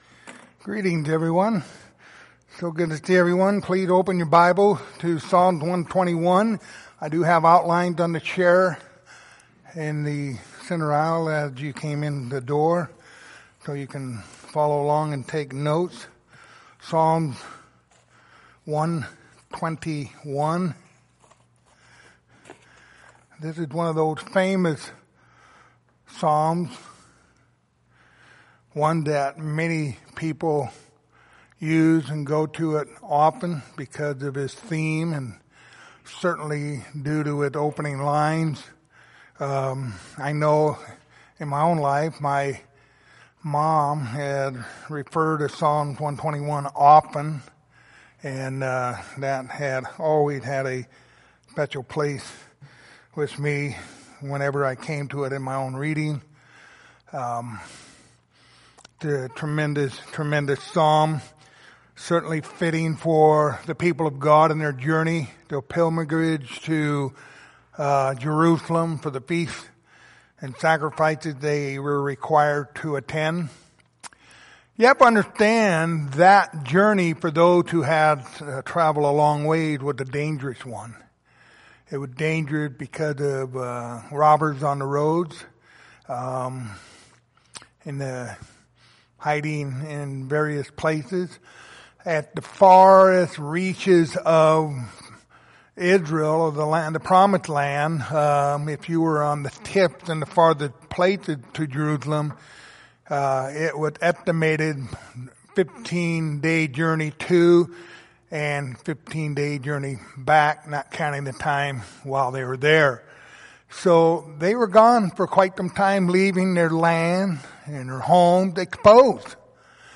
The book of Psalms Passage: Psalms 121:1-8 Service Type: Sunday Evening Topics